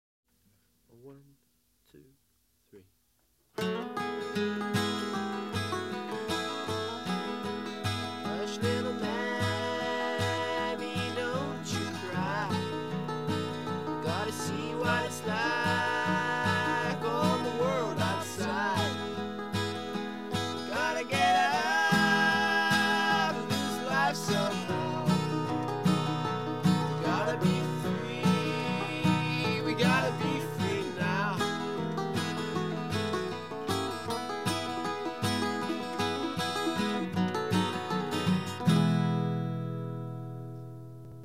Британская рок-группа